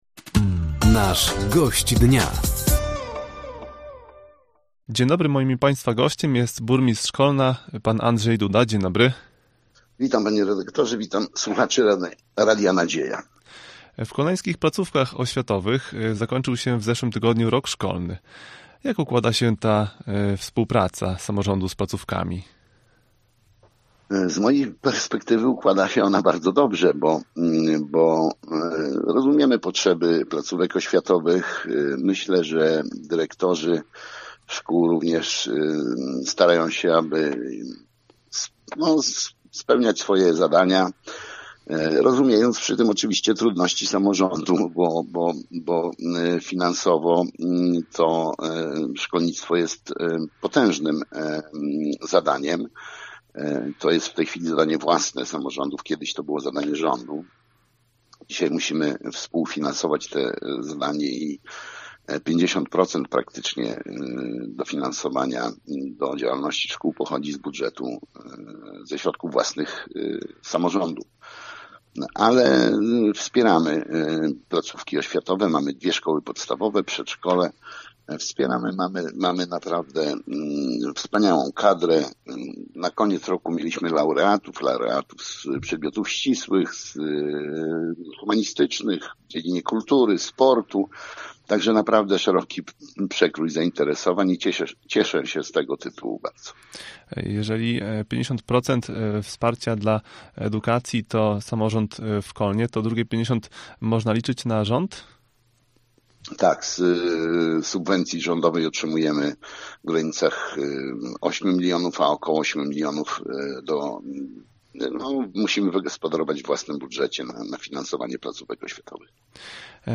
Gościem Dnia Radia Nadzieja był Burmistrz Kolna, Andrzej Duda. Tematem rozmowy była współpraca z oświatą, otwarcie jednostki wojskowej czy też wspólny projekt „Wiele Gmin – jeden cel”.